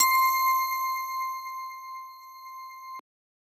Bell 2 (Official).wav